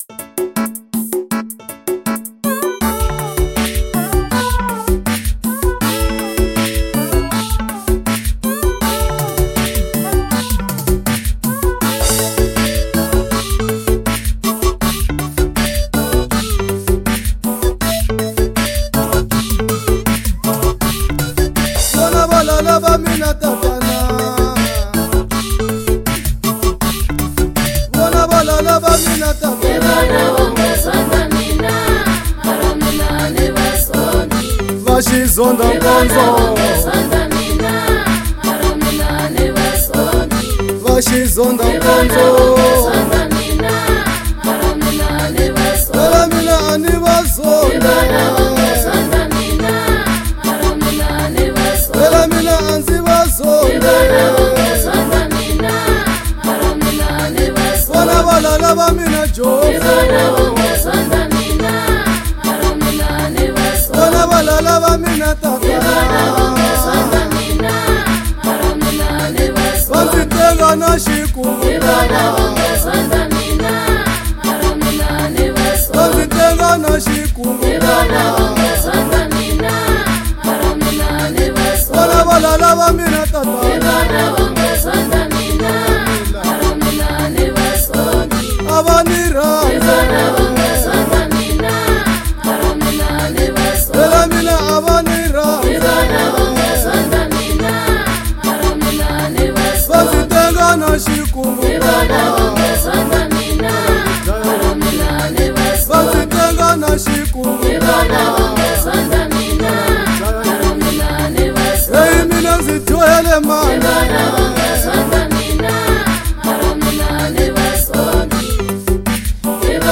06:21 Genre : Gospel Size